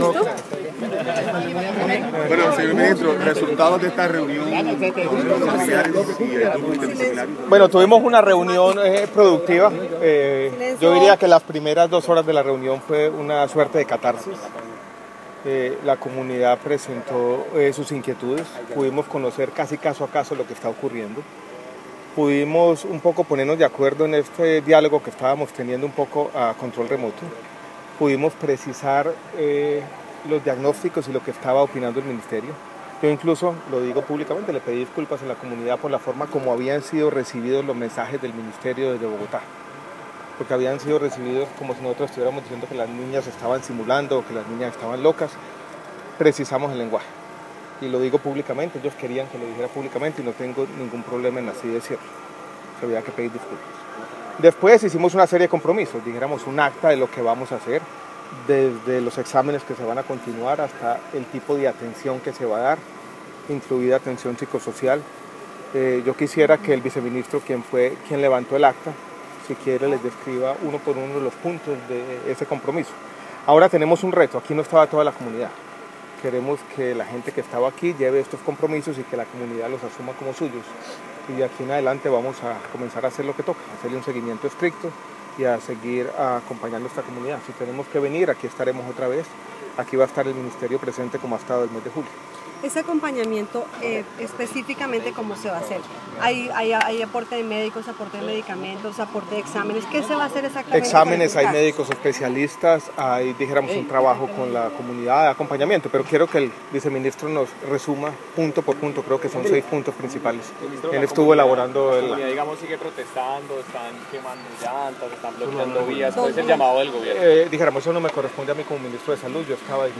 Audio, Conclusiones del MinSalud tras reunión de comunidad de El Carmen de Bolívar